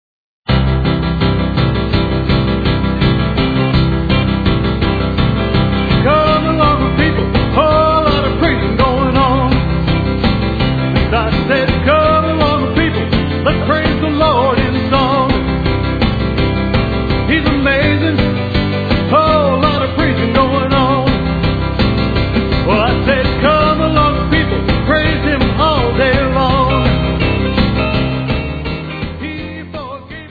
You will love the upbeat music and fun Christian message.